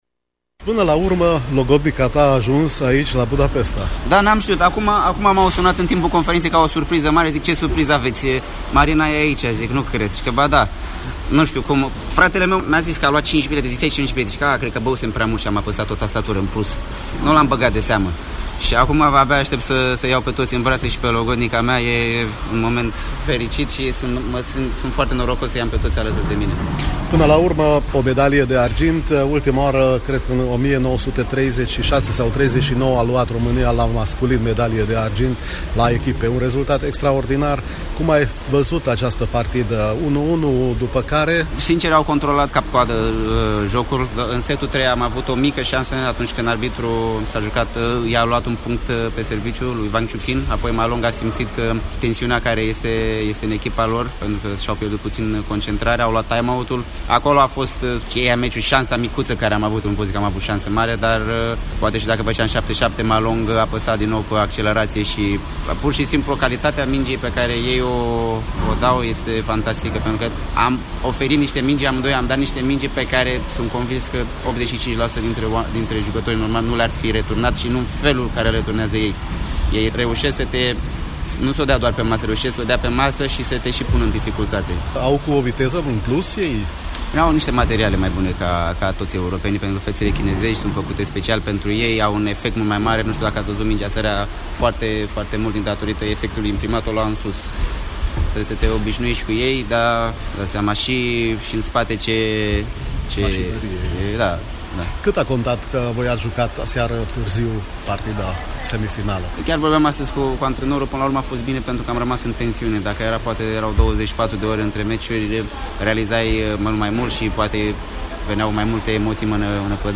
Un interviu audio cu Ovidiu Ionescu
imediat după finala de sâmbătă, de la Mondiale